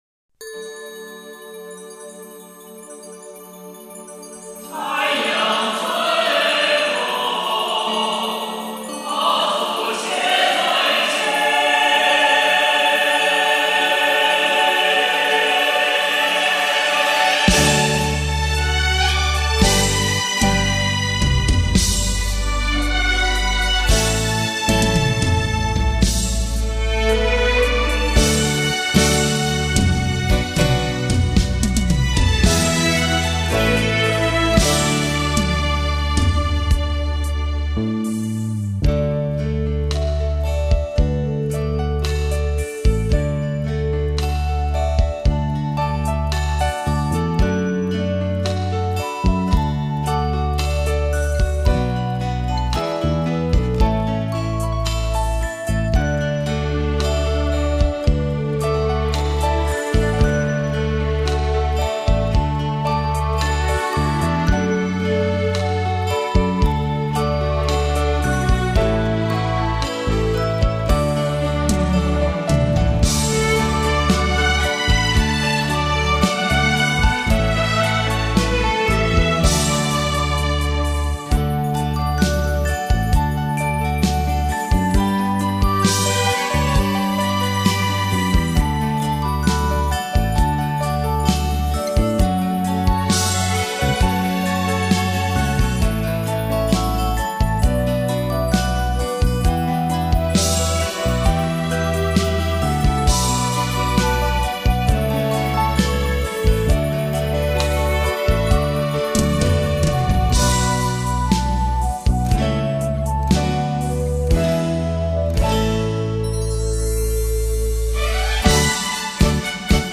（伴奏）